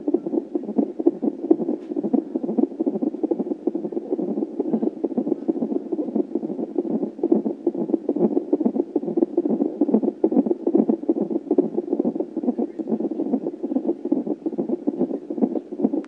Heart beat